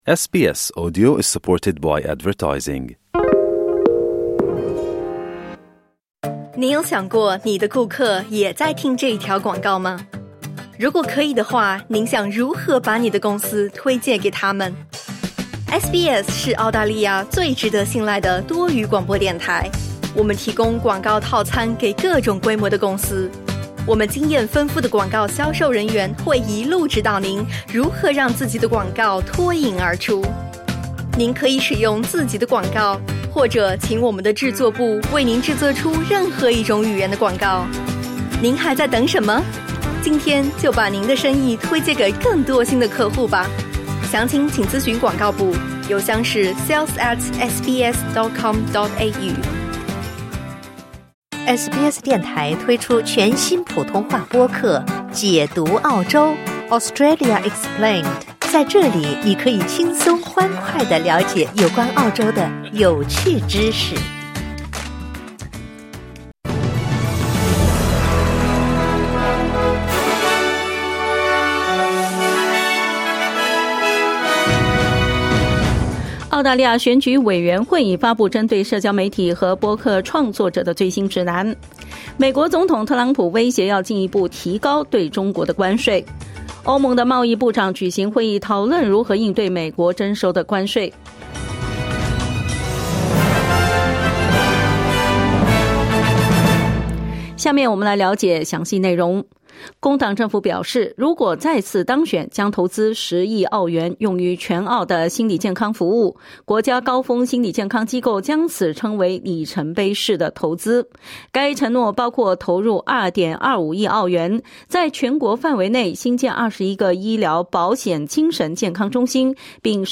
SBS早新闻（2025年4月8日）